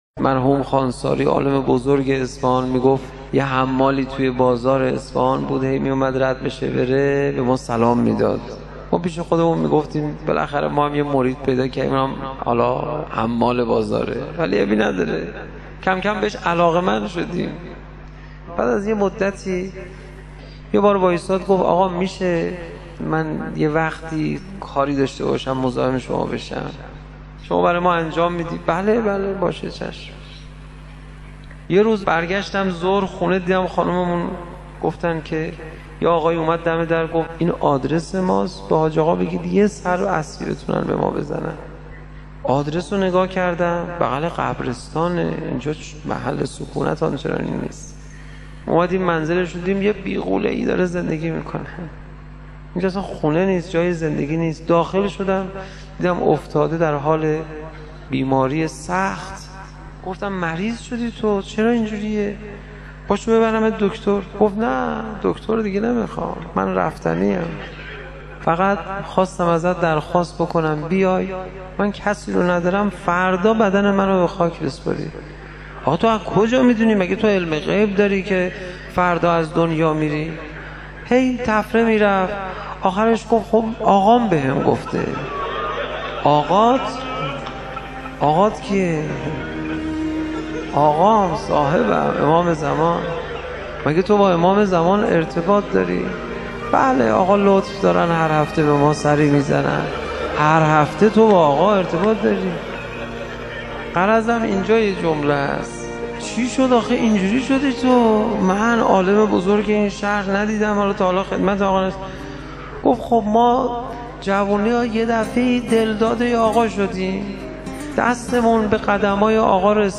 سخنرانی | داستان حمالی که در جوانی دلداده امام زمان(عج) شد